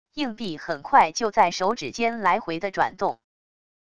硬币很快就在手指间来回的转动wav下载